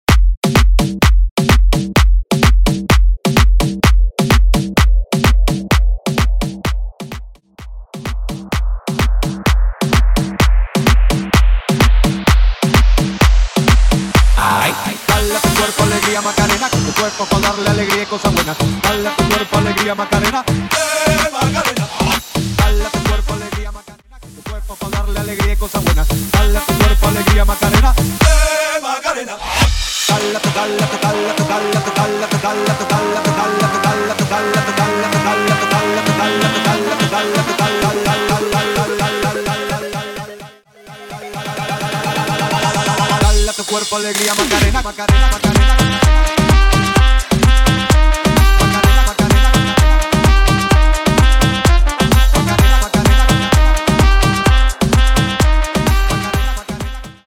Specializing in Latin genres